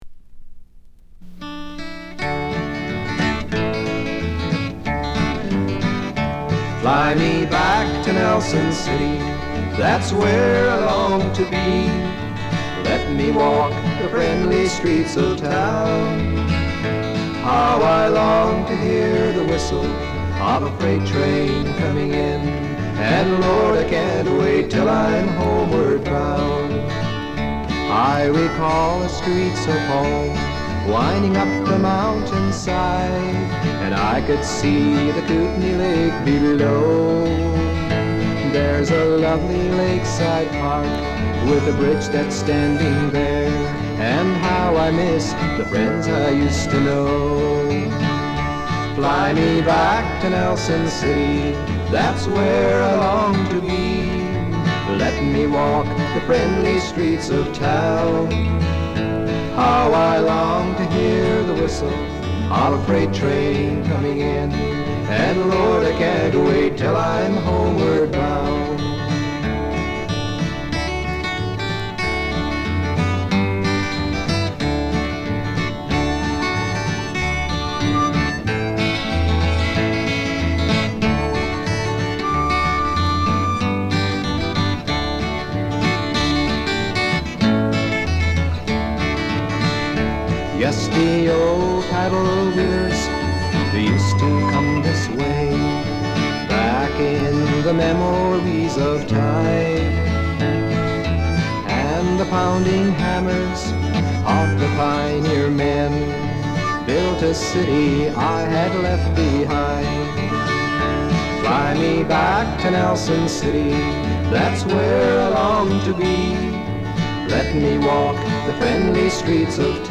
Doukhobor singer-songwriter